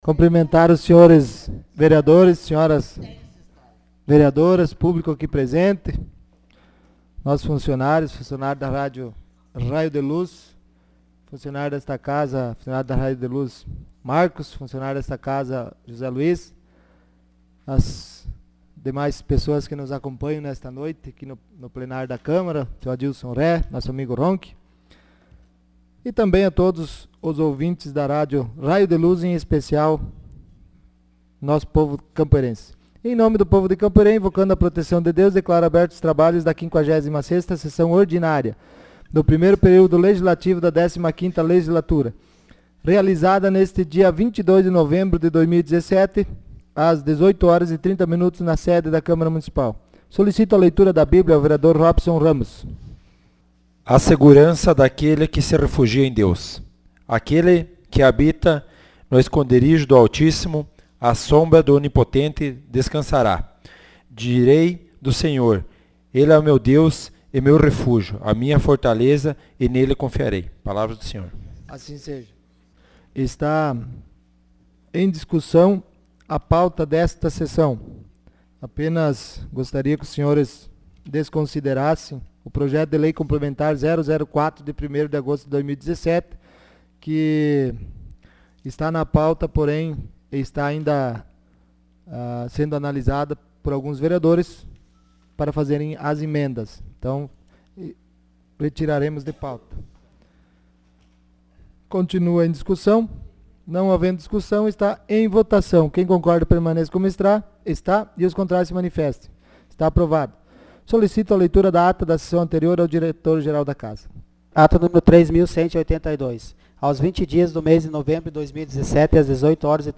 Sessão Ordinária dia 22 de novembro de 2017.